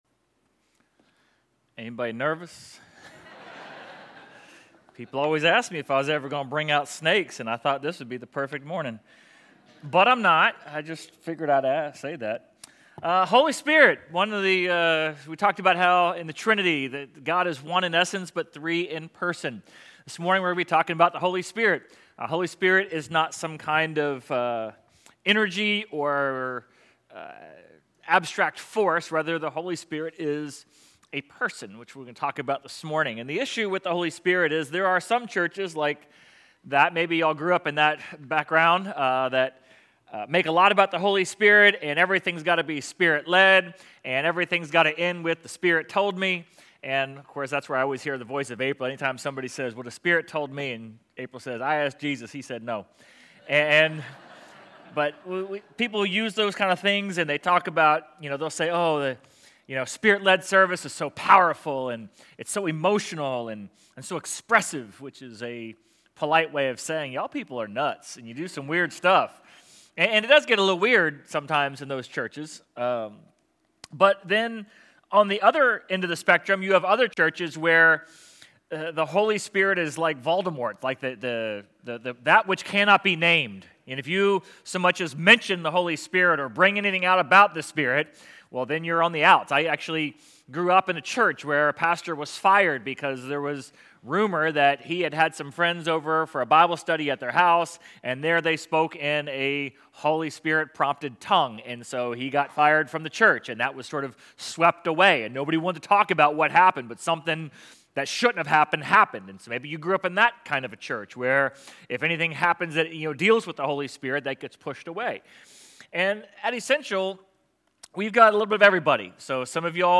Sermon_6.29.25.mp3